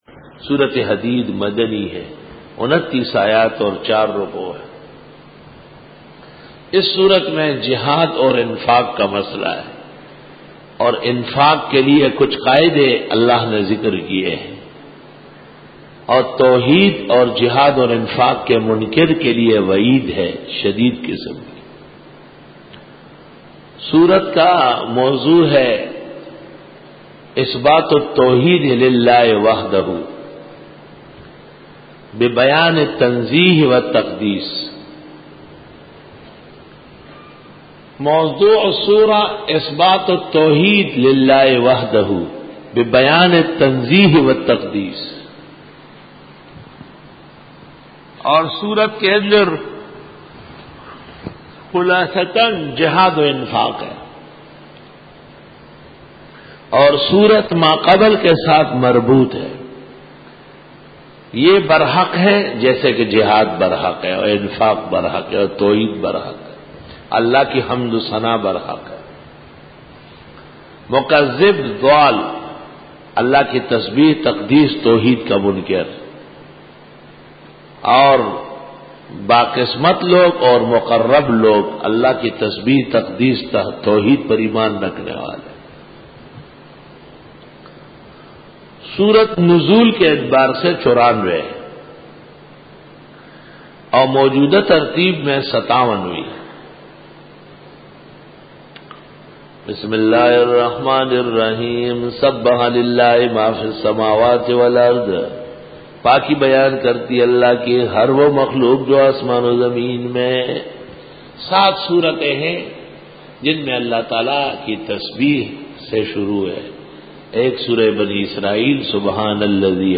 Dora-e-Tafseer 2006